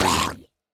assets / minecraft / sounds / mob / drowned / hurt3.ogg
hurt3.ogg